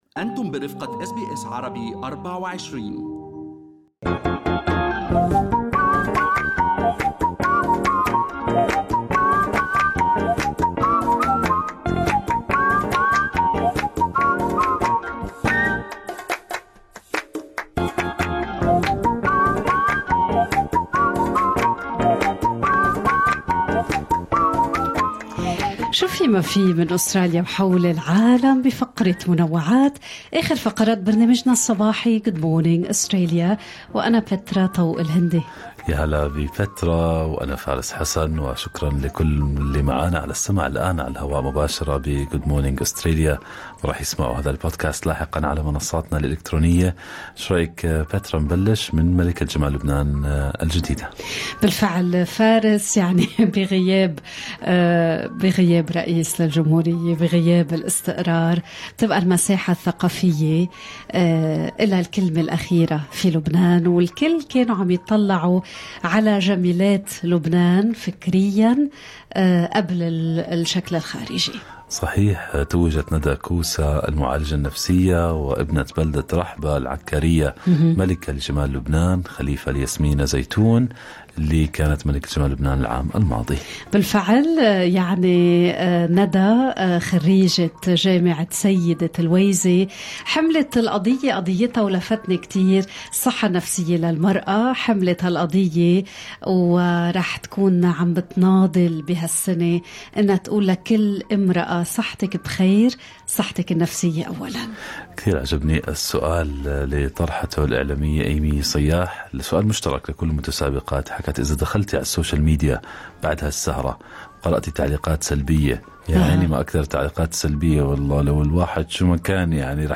نقدم لكم فقرة المنوعات من برنامج Good Morning Australia التي تحمل إليكم بعض الأخبار والمواضيع الأكثر رواجا على مواقع التواصل الاجتماعي.